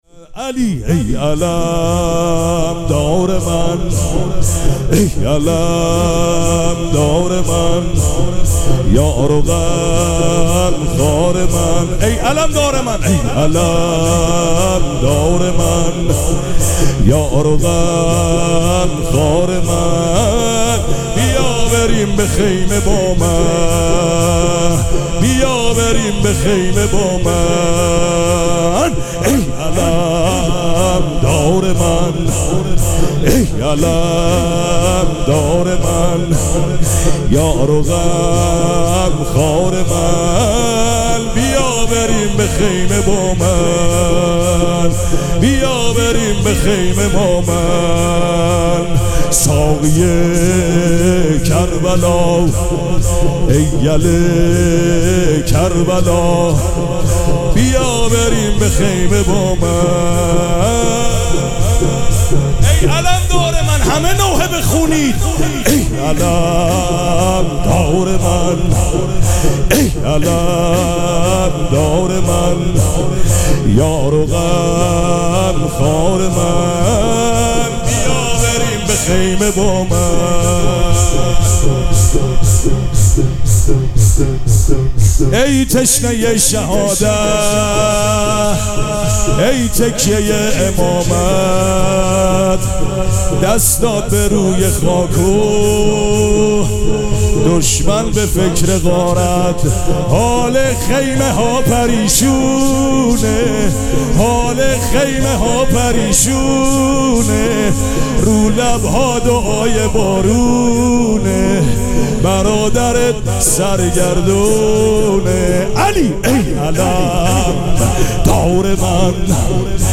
شب چهارم مراسم عزاداری اربعین حسینی ۱۴۴۷
شور